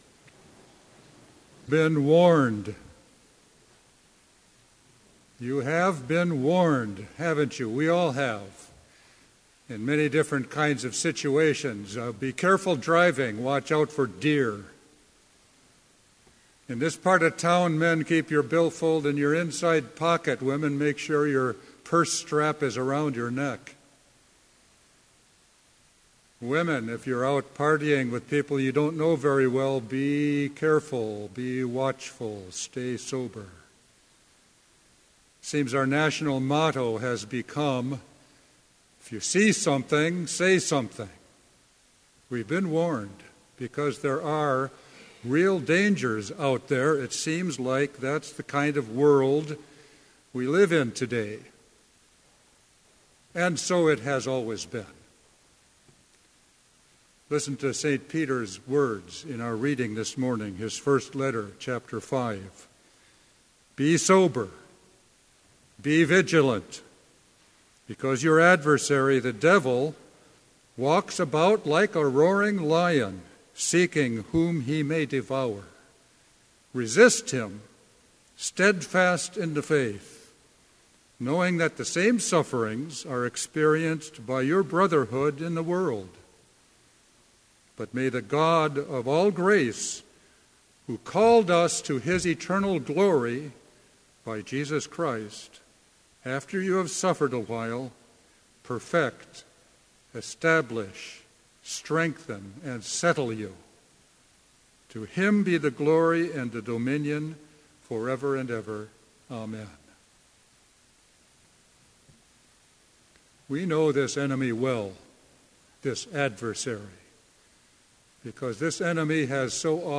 Complete Service
This Chapel Service was held in Trinity Chapel at Bethany Lutheran College on Tuesday, March 1, 2016, at 10 a.m. Page and hymn numbers are from the Evangelical Lutheran Hymnary.